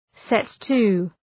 Shkrimi fonetik {‘set,tu:}